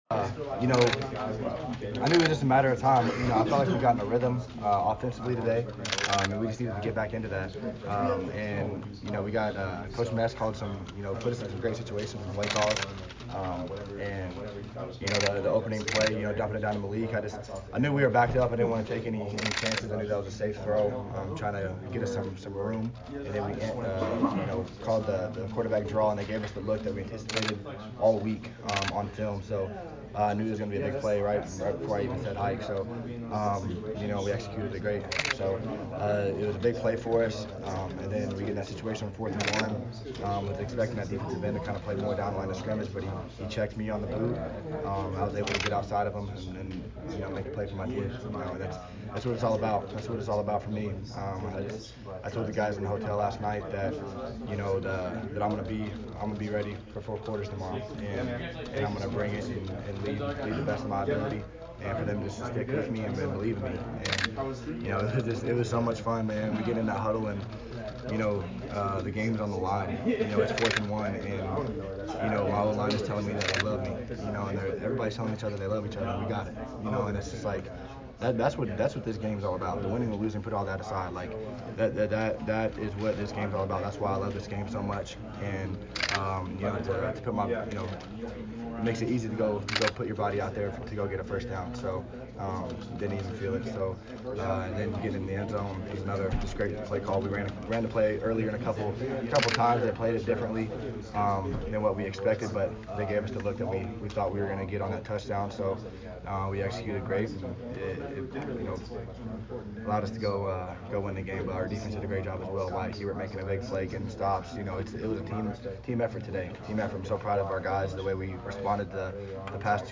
POST GAME AUDIO